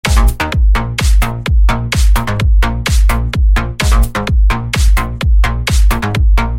ритмичные
громкие
EDM
electro house
цикличный